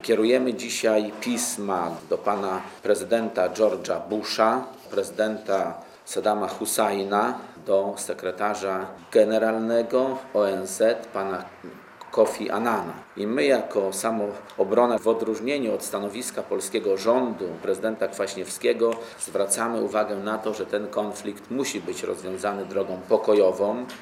Na konferencji u Leppera był reporter Radia Zet (752Kb)